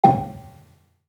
Gambang-G4-f.wav